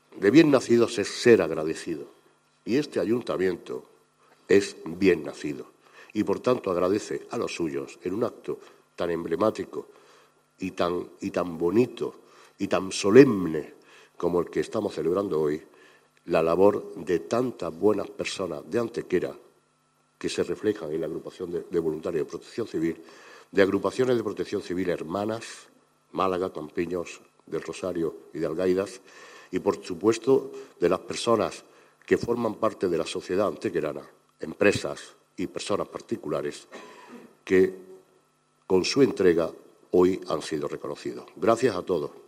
Por su parte, el alcalde Manolo Barón ha cerrado el acto felicitnado a todos los galardonados en el día de hoy, mostrando su especial satisfacción por el magnífico elenco de voluntarios que en su conjunto dispone Protección Civil en Antequera y el valioso servicio que ofrecen a nuestros vecinos en colaboración con el resto de fuerzas y cuerpos de seguridad.
Cortes de voz